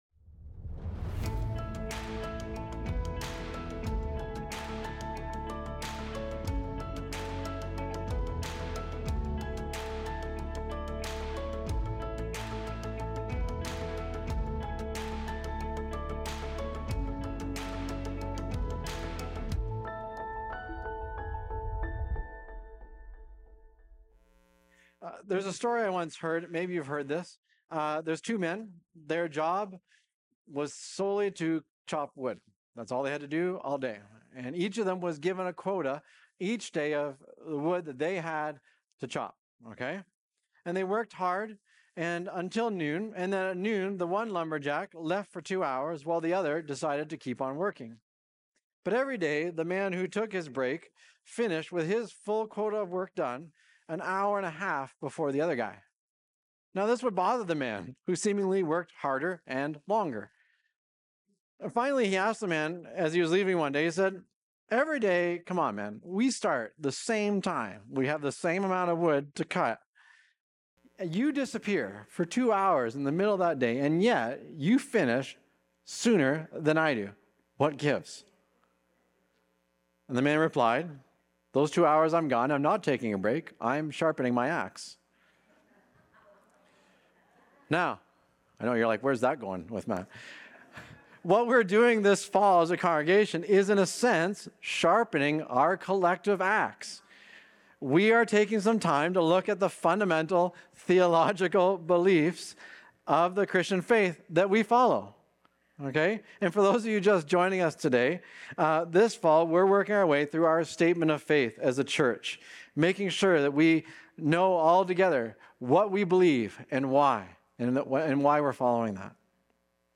Recorded Sunday, October 19, 2025, at Trentside Fenelon Falls.